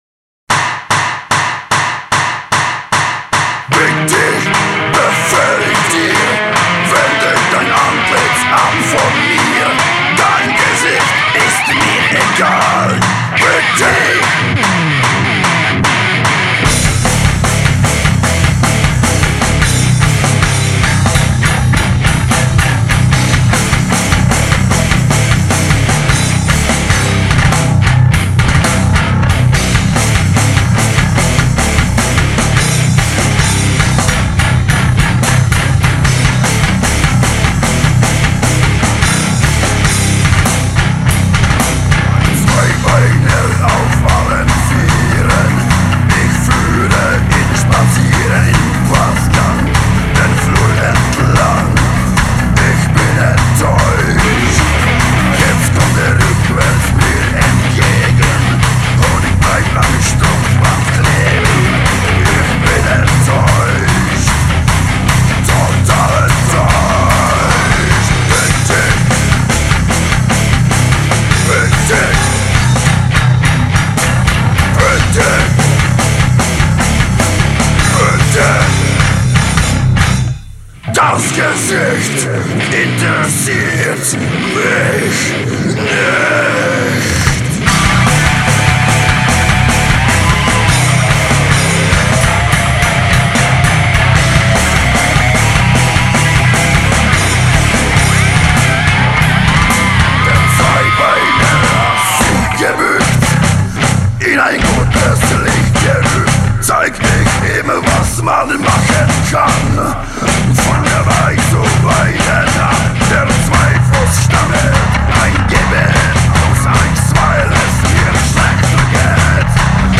Категорія : Рок